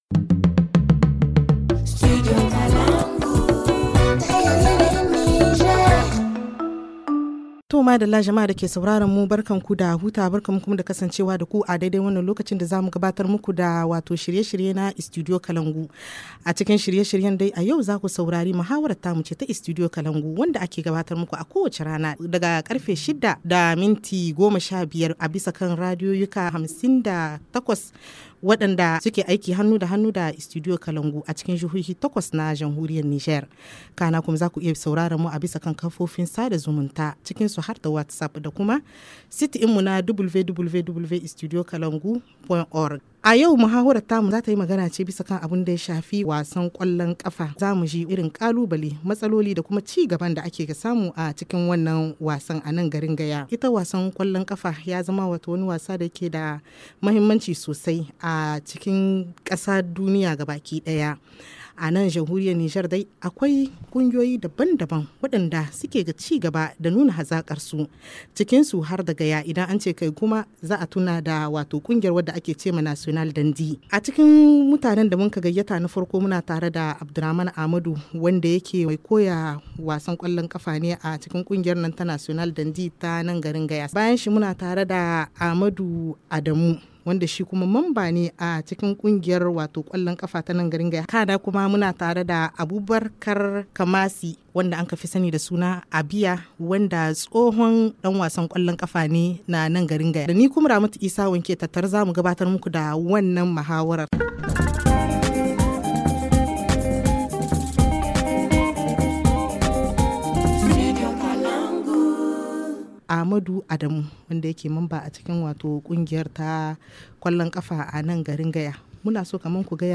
HA Le forum en haoussa Télécharger le forum ici.